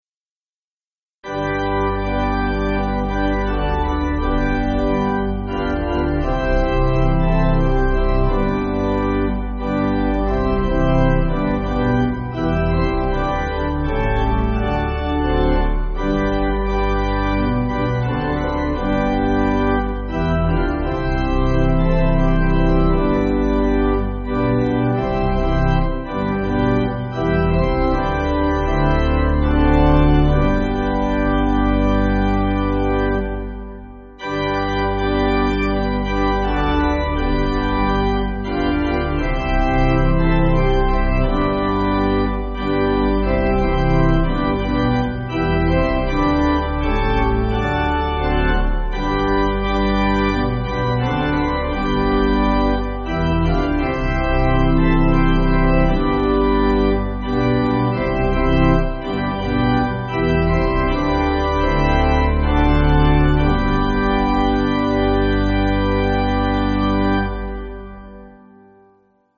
Organ
(CM)   2/G